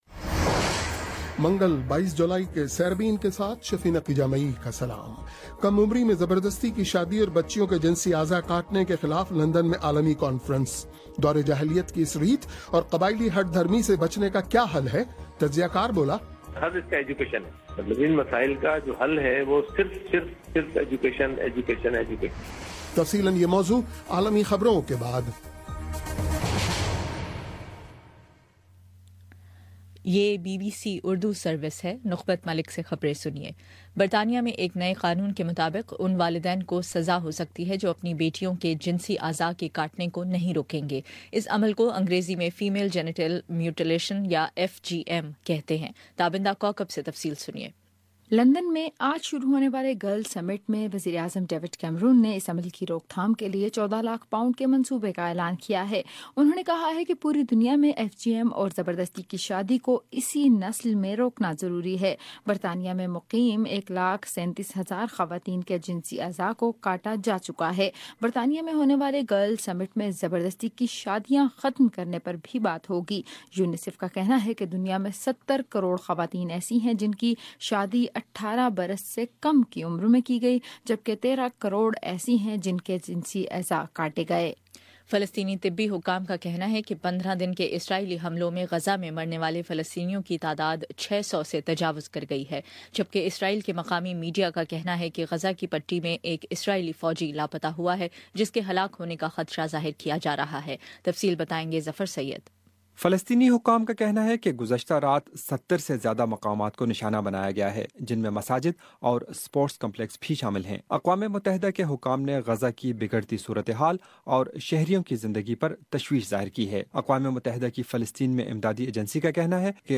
پیر بائیس جولائی کا سیربین ریڈیو پروگرام